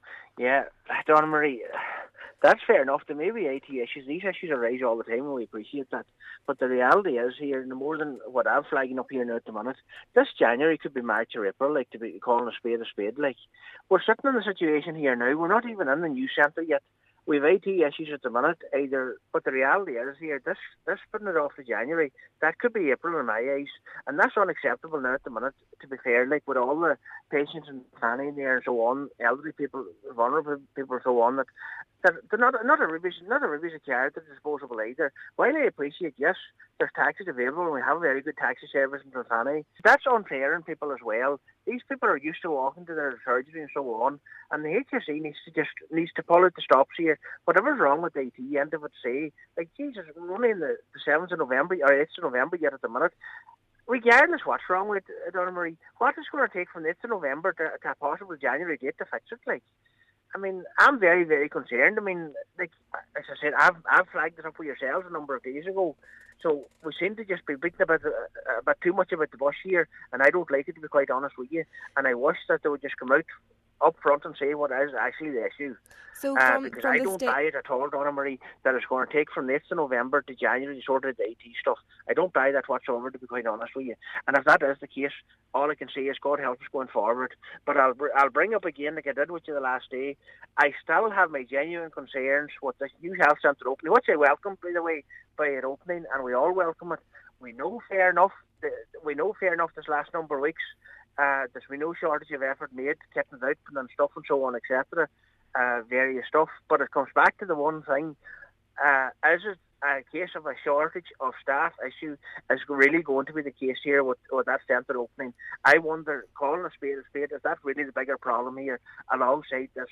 Cllr McClafferty says this is something he does not have confidence in: